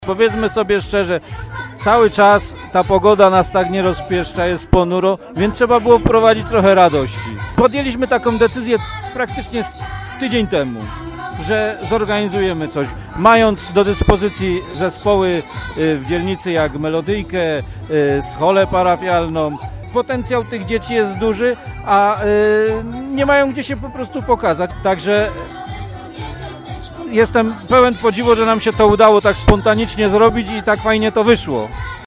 – Organizacja tego wydarzenia to spontaniczny pomysł – opowiada przewodniczący Rady Osiedla Lipnik Sławomir Glaser.